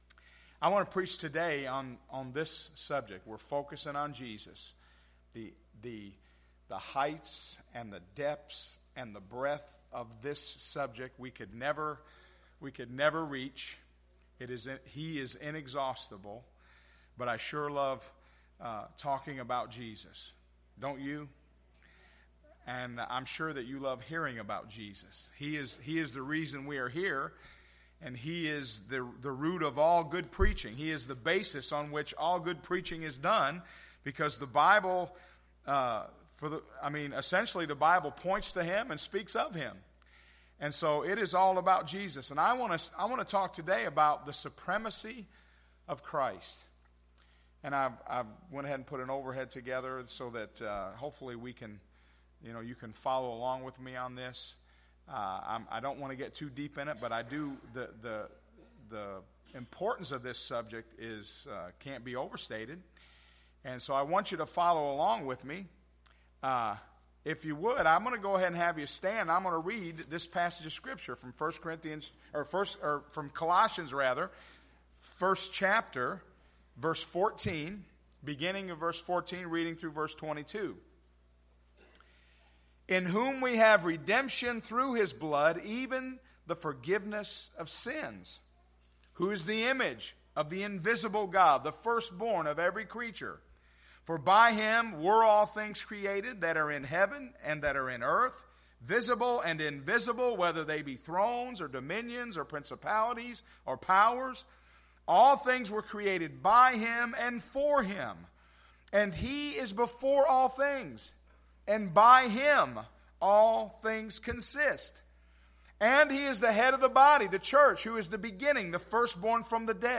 Sunday Morning - 3/6/2016 — Unity Free Will Baptist Church